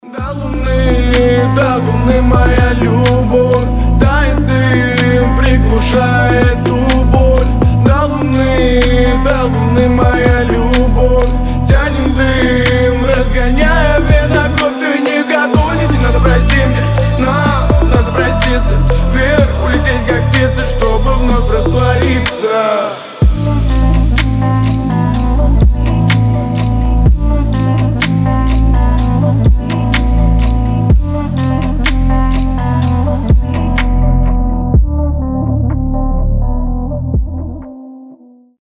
• Качество: 320, Stereo
грустные
атмосферные